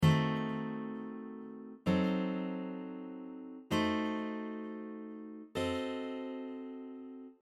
Diminished 7th chord inversions
See and hear the following example which uses the same chord shape to play four other diminished chords containing the same notes.